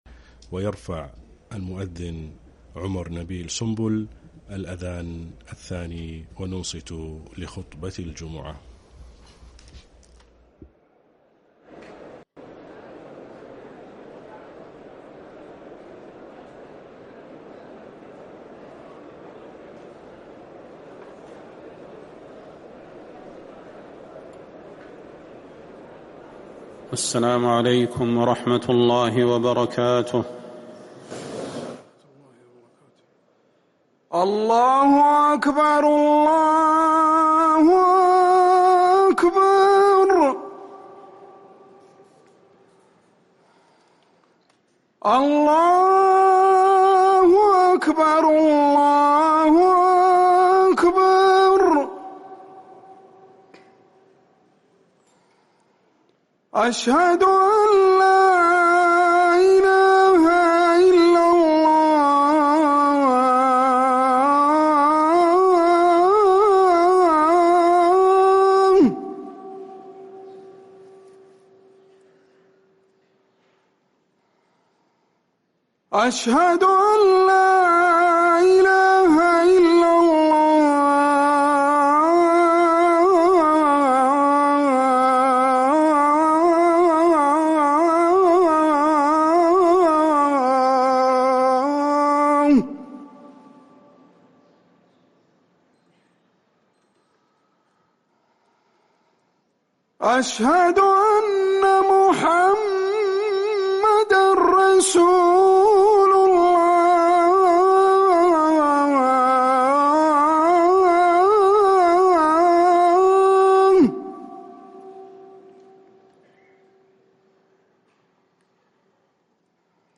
أذان الجمعة الثاني للمؤذن